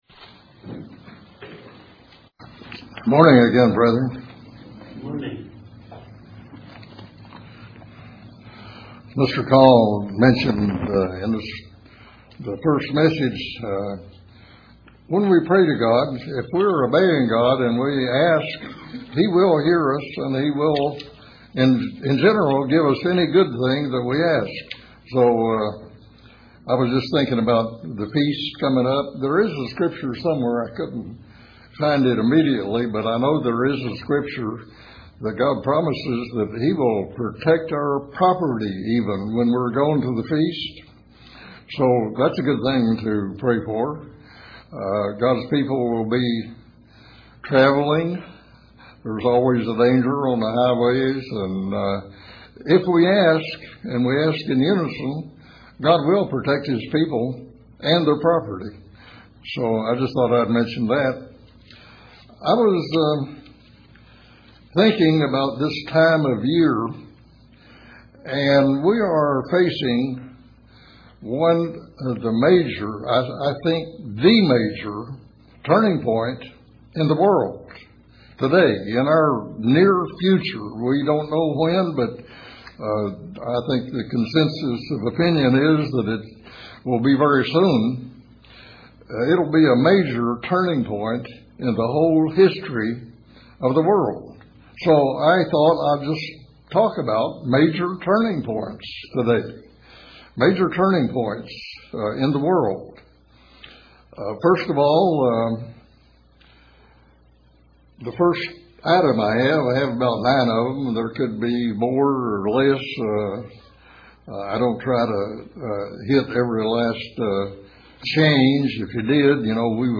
Sermons
Given in Paintsville, KY